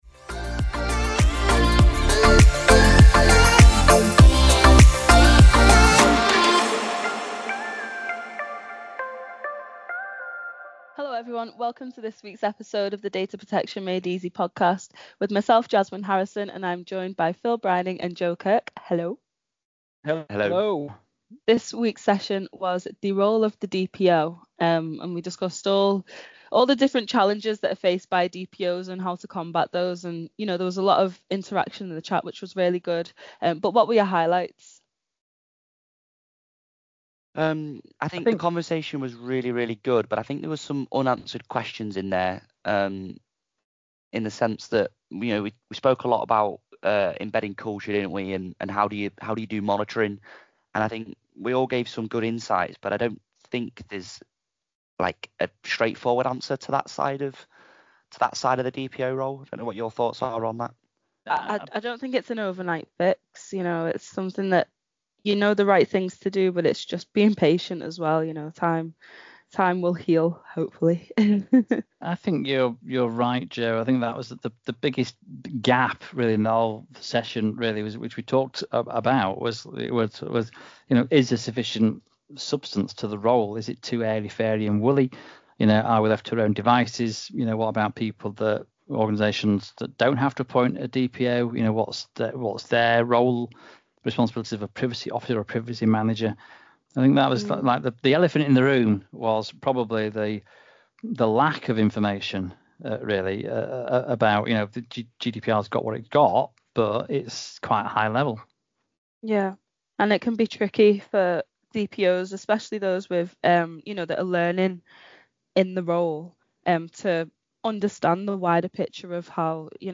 If you enjoy listening to this episode and would like to join us live on future episodes of the Data Protection Made Easy podcast you can visit our upcoming events page and register for any of the insightful events we have on the horizon.